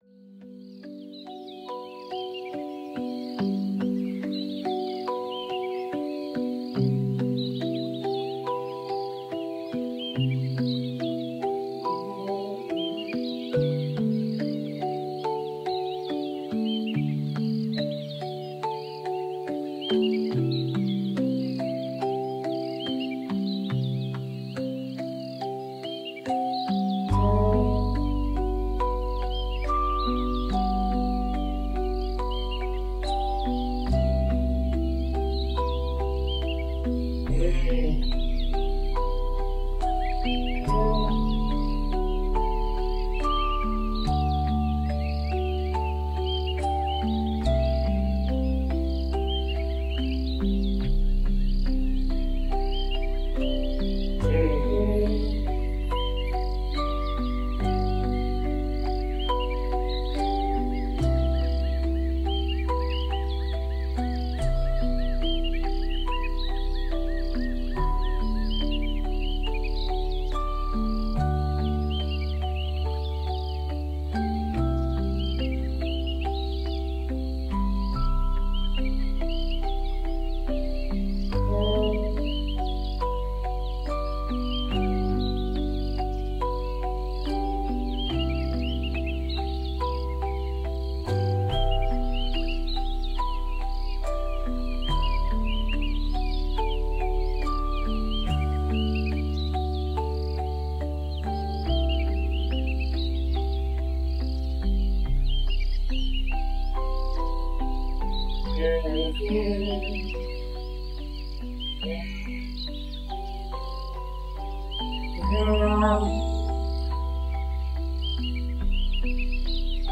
This sample of music was composed with Patas Monkeys in mind. This is called “Species-Specific Music”! It aims to promote relaxation, encourage natural behaviors, and improve overall welfare. The notes are played at 140 bpm which is the same as…